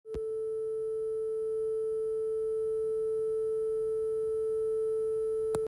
No clicks